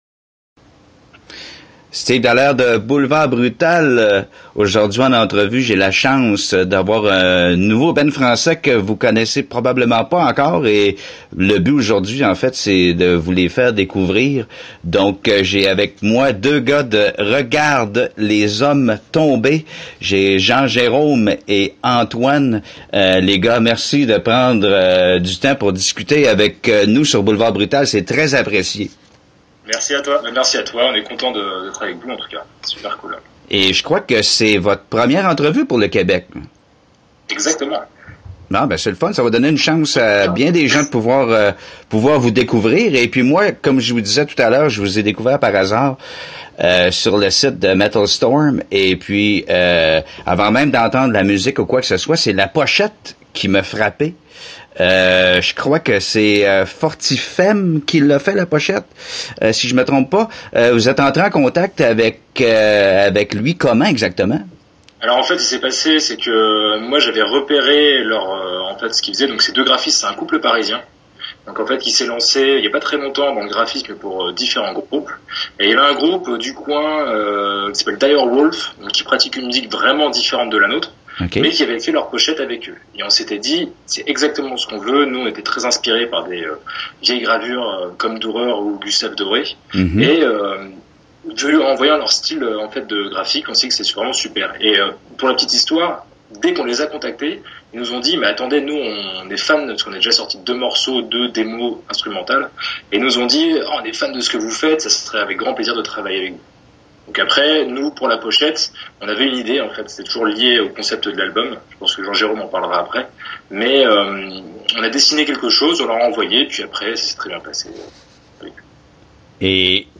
Entrevue exclusive avec Regarde Les Hommes Tomber